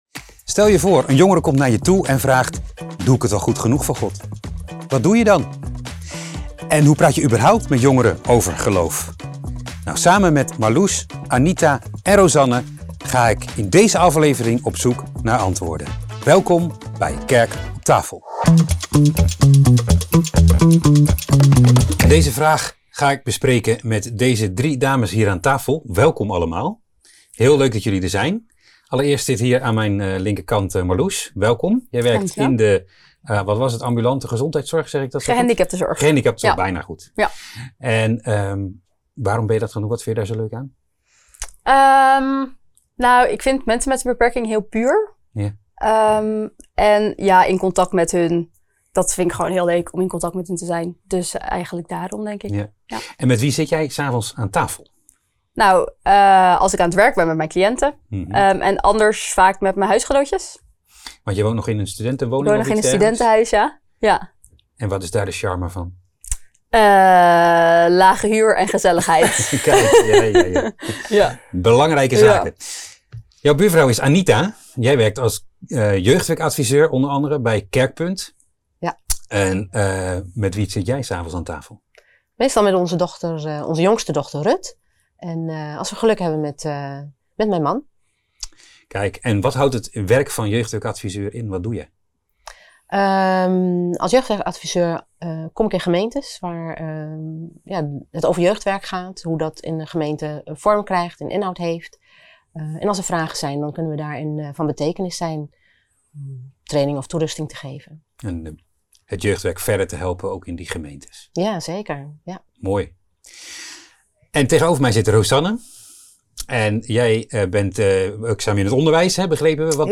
In de eerste van vier pilot afleveringen van de talkshow ‘Kerk op Tafel’ gaat het over de vraag: ‘wat doe je als jongeren aan je vragen ‘ben ik wel goed genoeg?’ Een vraag die regelmatig terugkeert in het jeugdwerk.